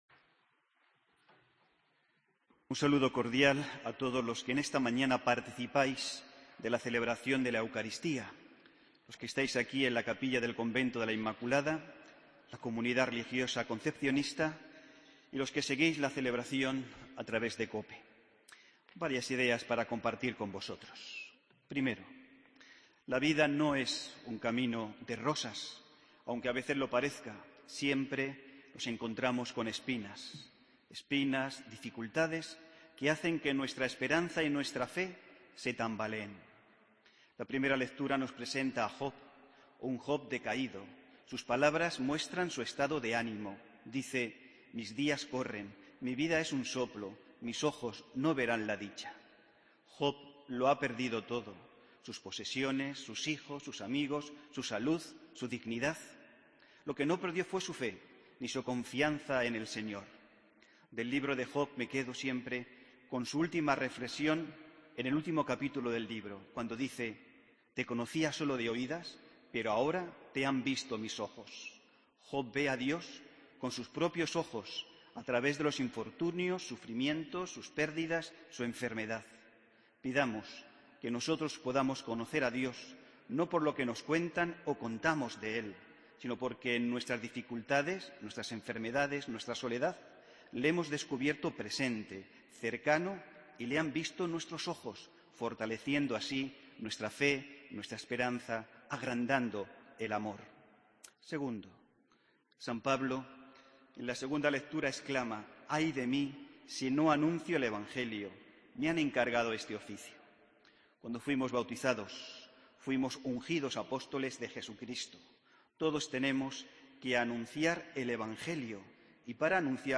Homilía del Domingo 8 de Febrero de 2015